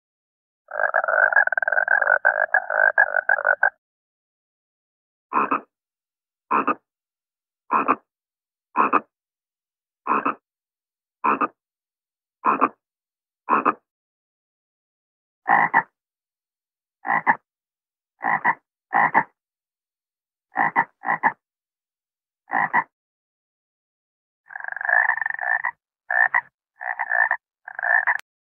دانلود آهنگ قورباغه 2 از افکت صوتی انسان و موجودات زنده
دانلود صدای قورباغه 2 از ساعد نیوز با لینک مستقیم و کیفیت بالا
جلوه های صوتی